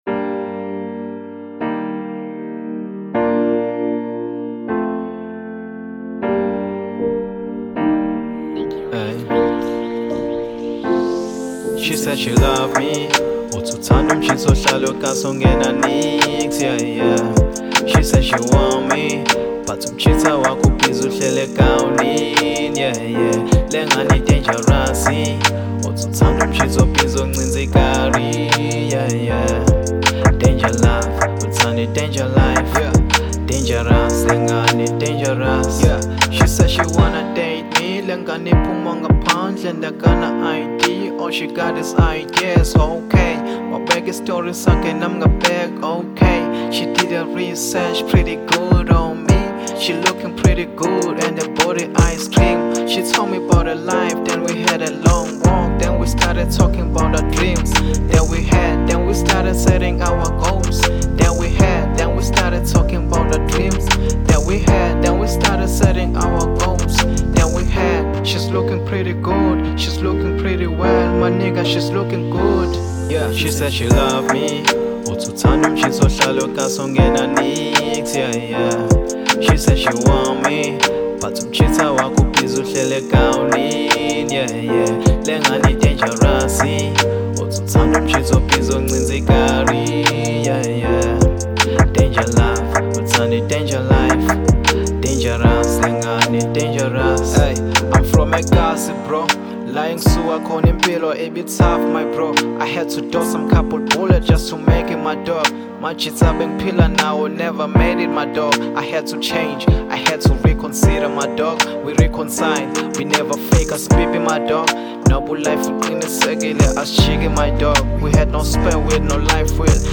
02:48 Genre : Hip Hop Size